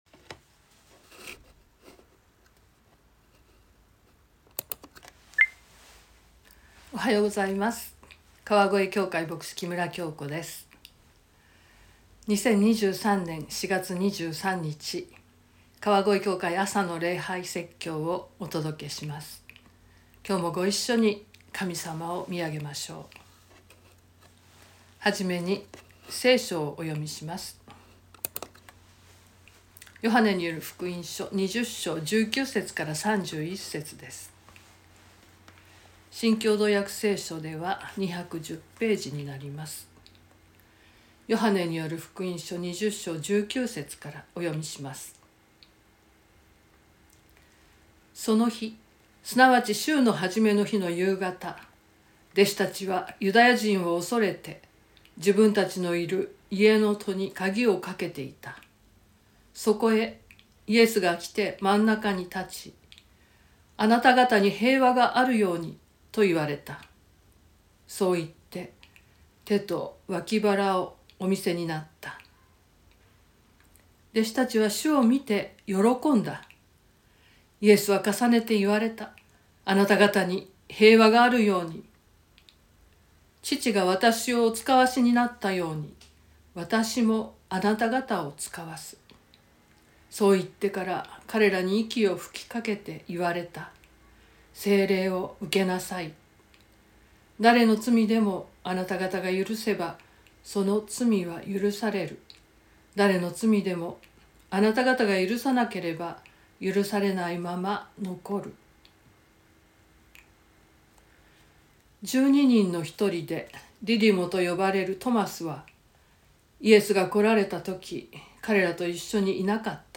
説教アーカイブ。
音声ファイル 礼拝説教を録音した音声ファイルを公開しています。